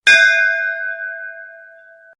PLAY Temple Bell
temple-bell-sound-effect-no-copyright-longer.mp3